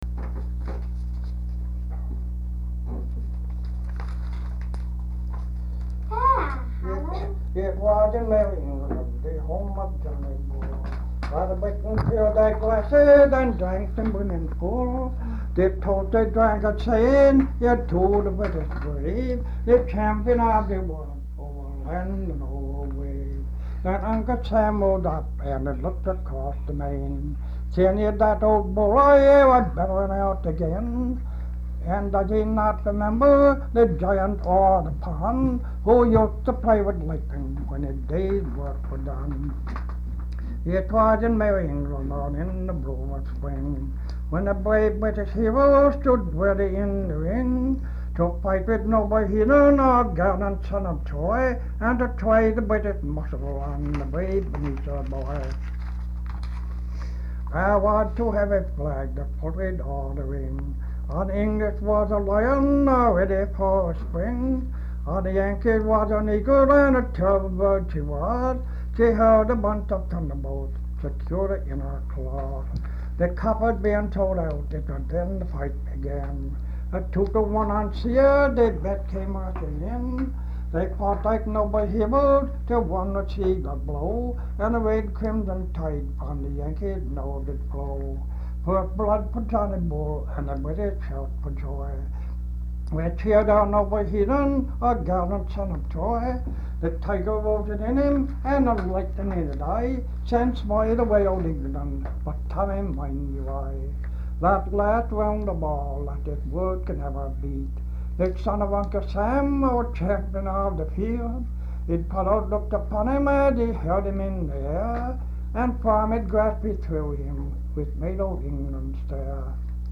folksongs
Folk songs, English--Vermont
sound tape reel (analog)